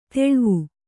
♪ teḷvu